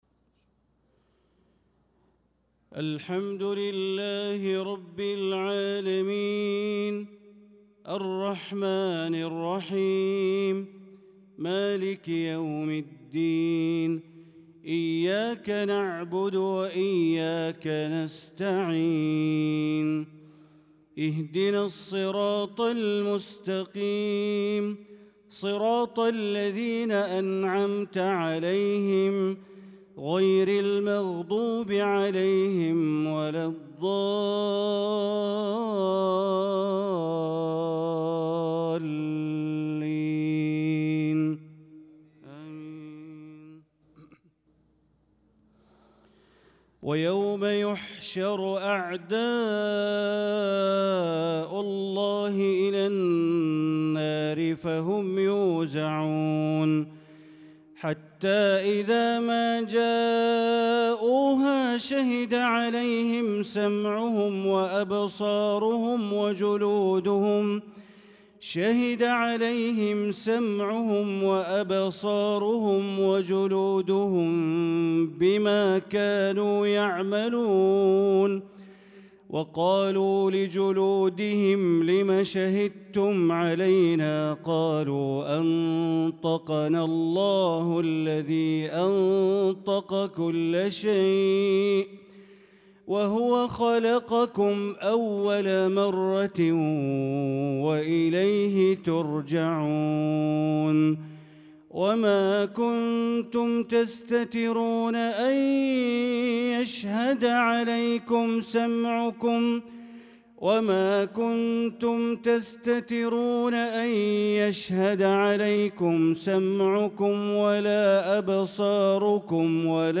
صلاة العشاء للقارئ بندر بليلة 28 شوال 1445 هـ